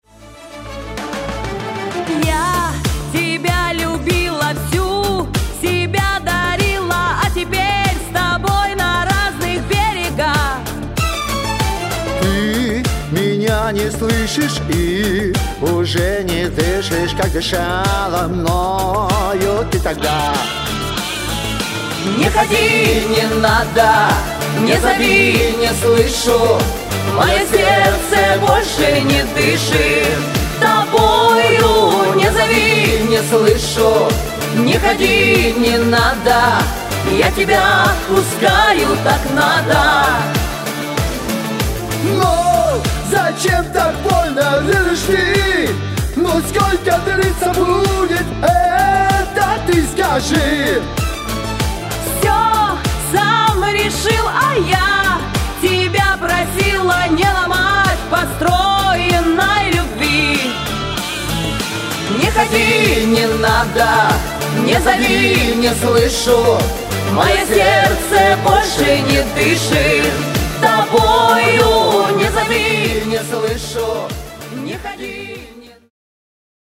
Не зови, не слышу ( запись и сведение)